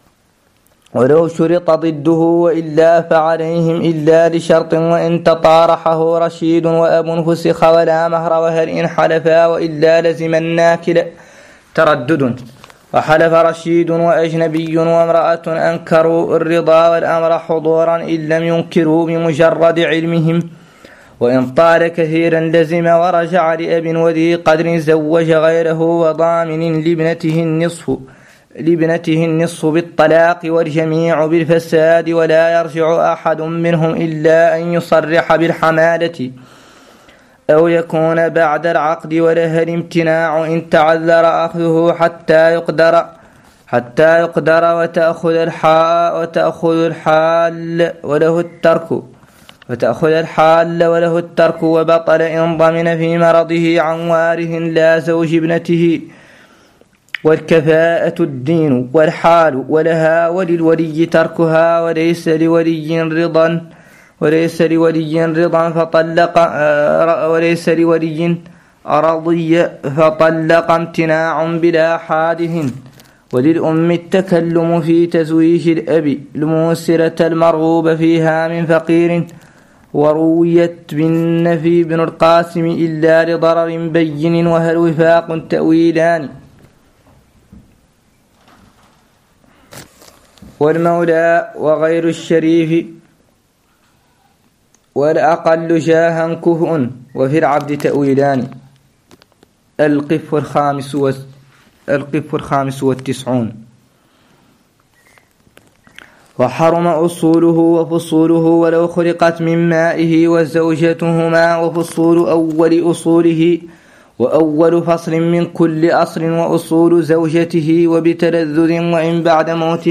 قراءة لمتن مختصر خليل 05